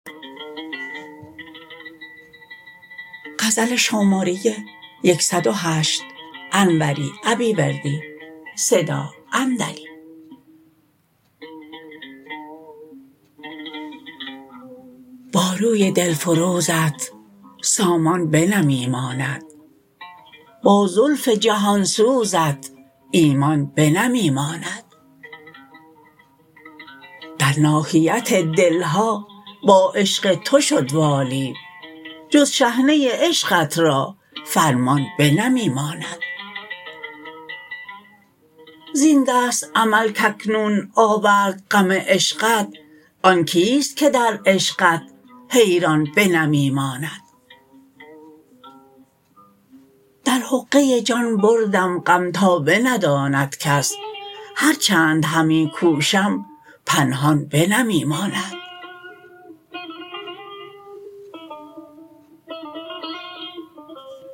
متن خوانش: